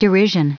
Prononciation du mot derision en anglais (fichier audio)
Prononciation du mot : derision